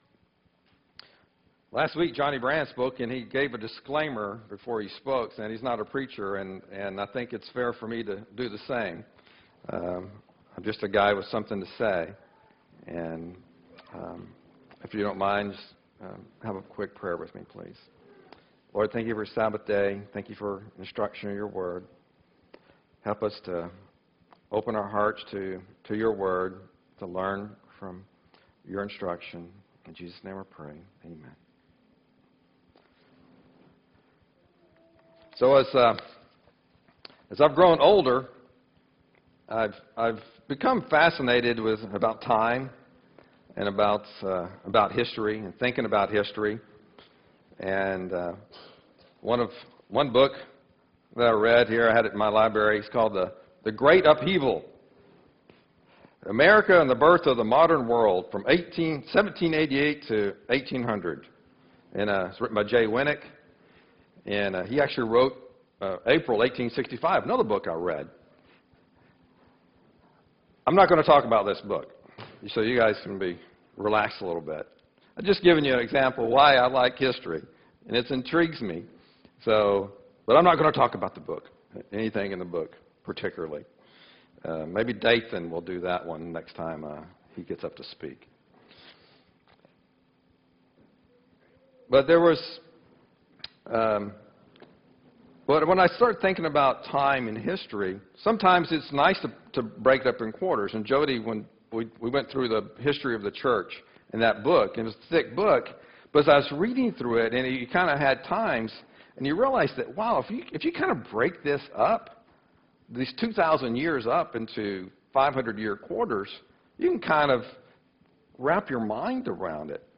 6-1-2013 sermon